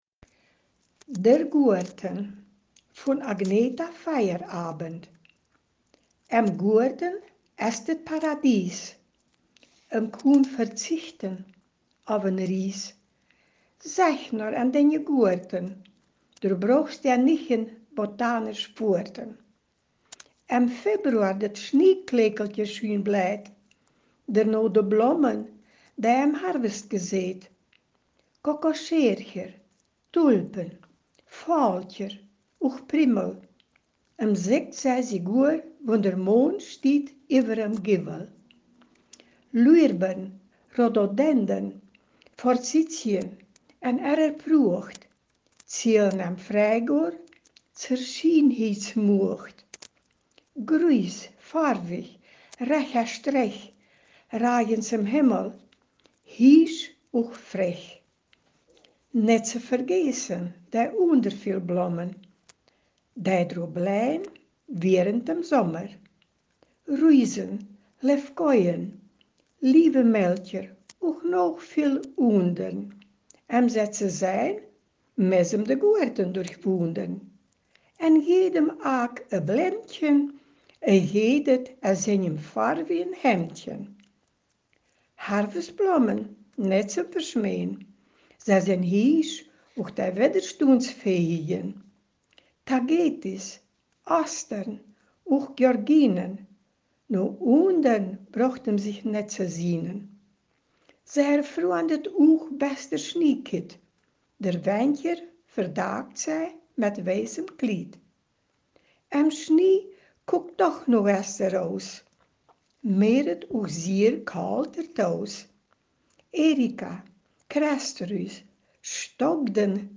Ortsmundart: Hamlesch